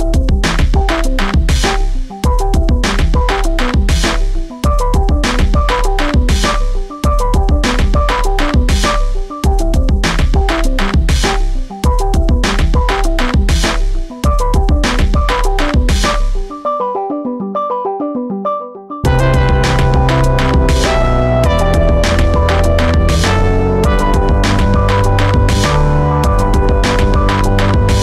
Featured in Electro RIngtones